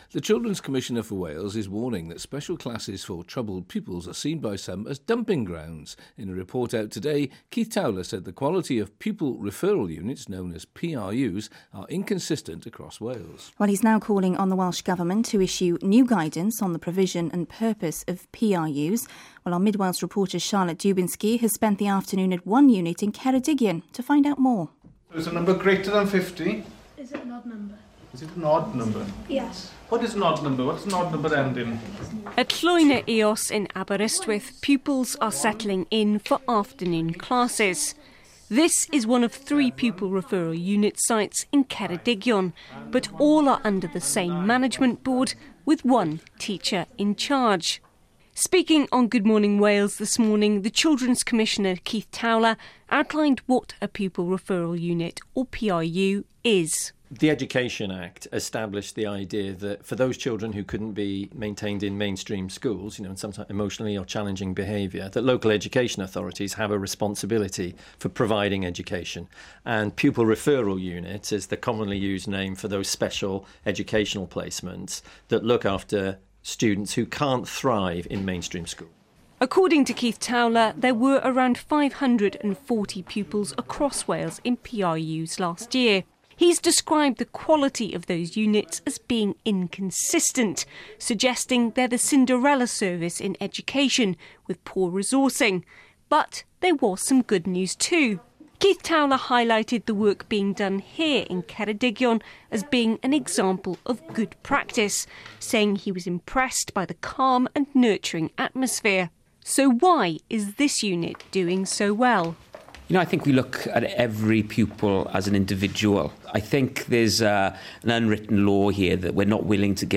has spent the afternoon at one unit in Ceredigion to find out more...